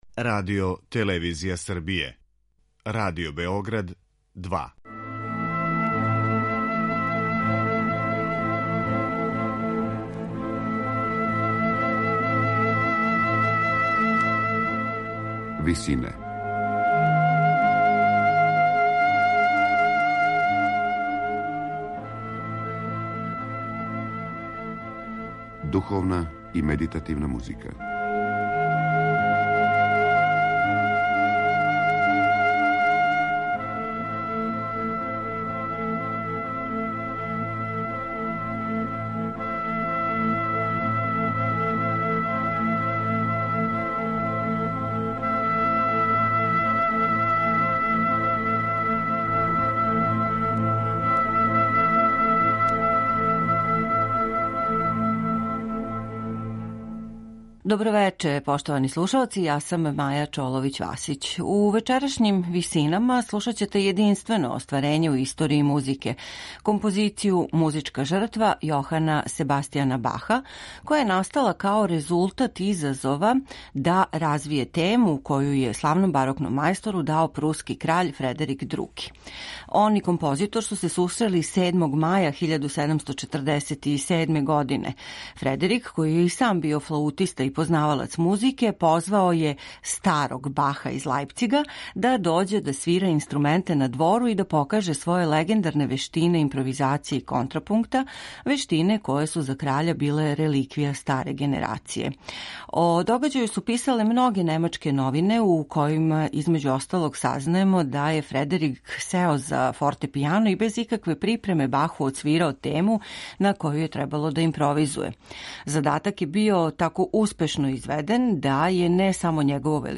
флаута
виолина
виола да гамба
чембало